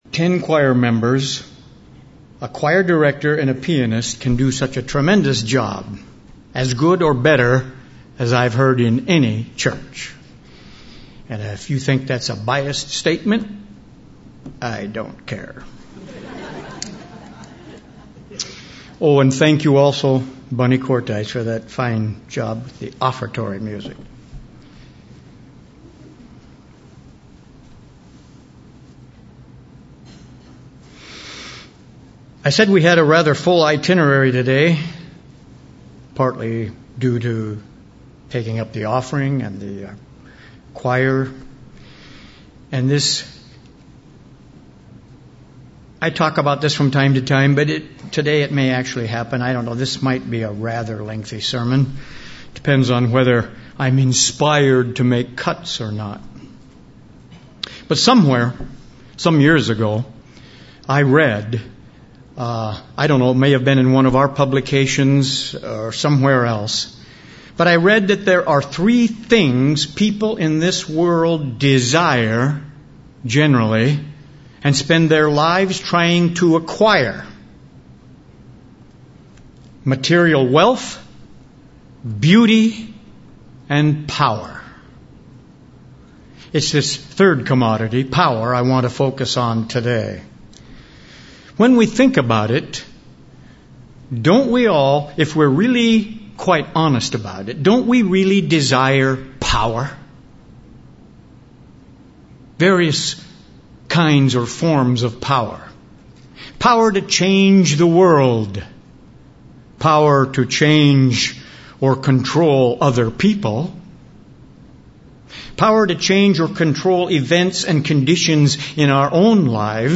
But God extends to us another kind of power now: the power of His Holy Spirit. This message was given on the Feast of Pentecost.
Given in Burlington, WA